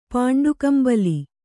♪ pāṇḍu kambali